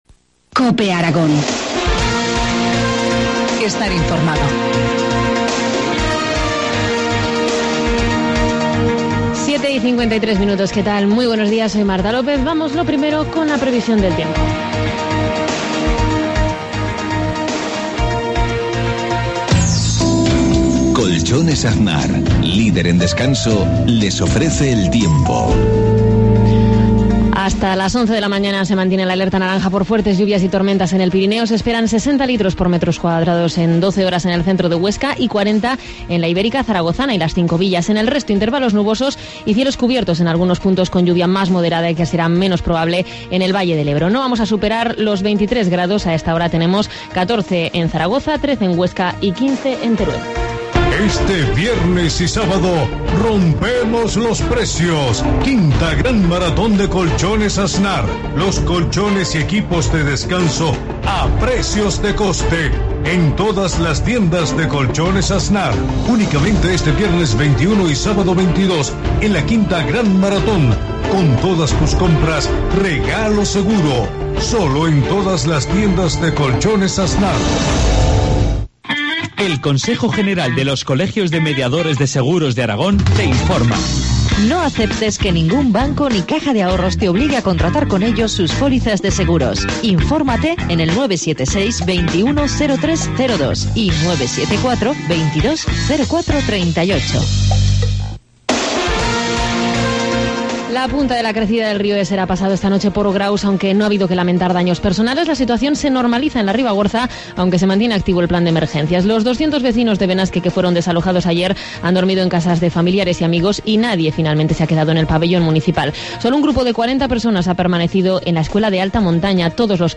Informativo matinal, 19 junio, 7,53 horas